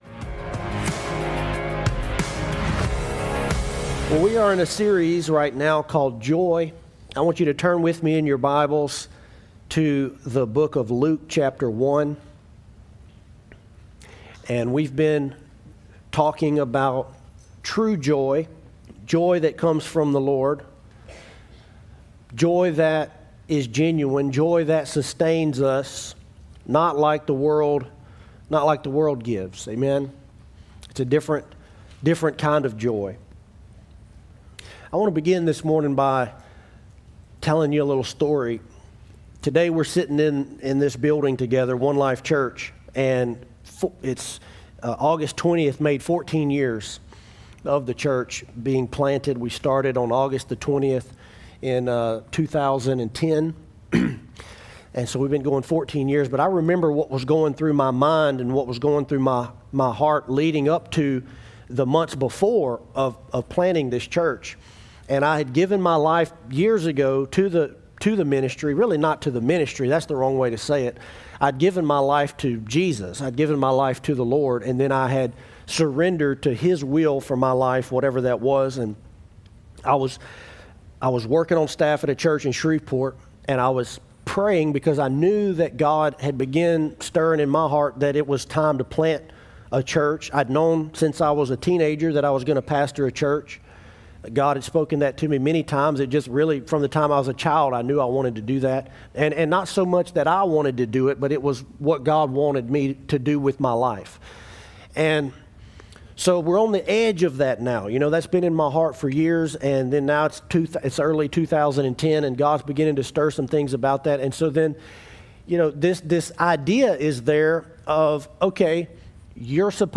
Sermons from One Life Church Alexandria